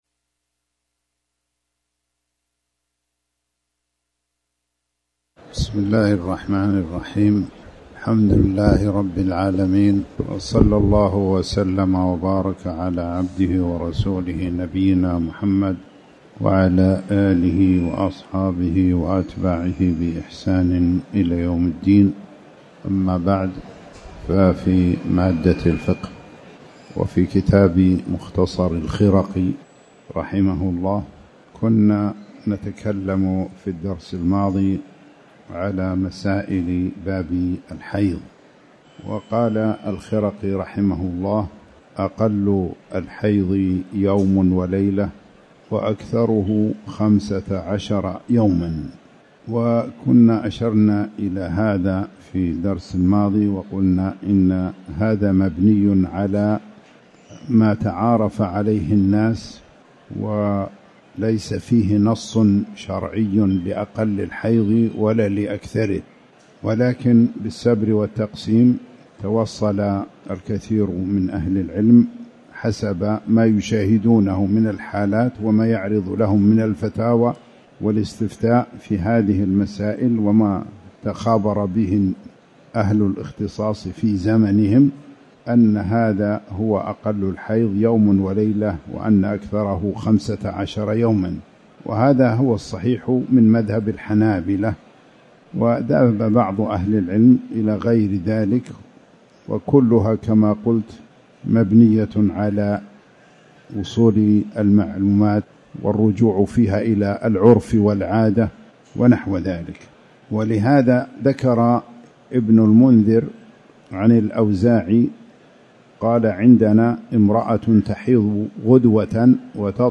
تاريخ النشر ١٥ صفر ١٤٤٠ هـ المكان: المسجد الحرام الشيخ